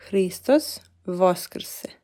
Hristos voskrse (tap for pronunciation).